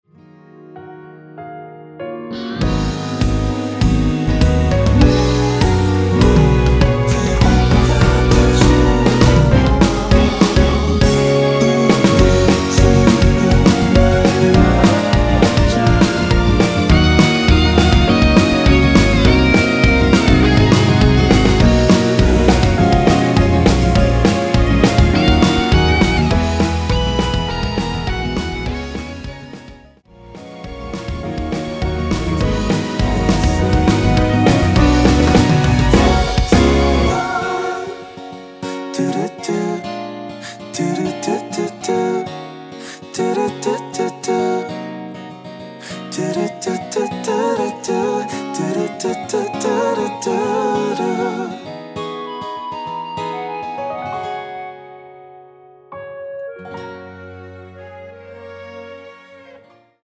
원키 멜로디와 코러스 포함된 MR입니다.(미리듣기 확인)
Gb
앞부분30초, 뒷부분30초씩 편집해서 올려 드리고 있습니다.
중간에 음이 끈어지고 다시 나오는 이유는